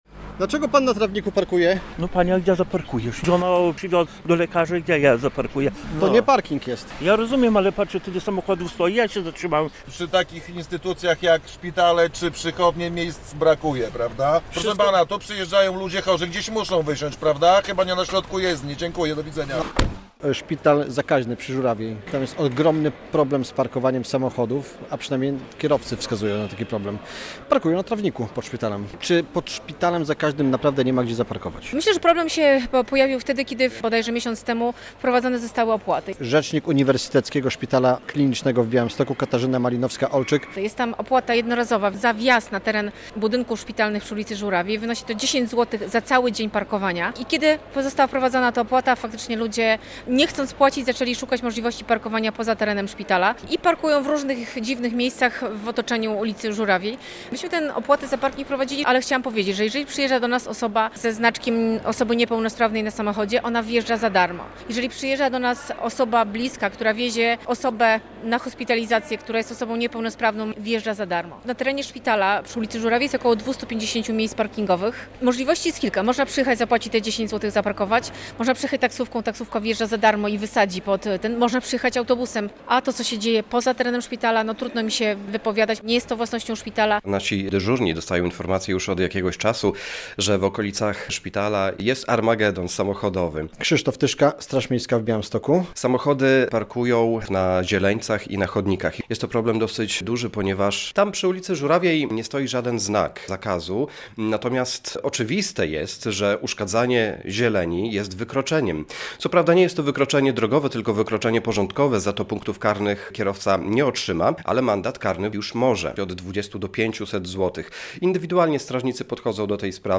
Wiadomości - Byle gdzie, aby za darmo - dziki parking przy szpitalu zakaźnym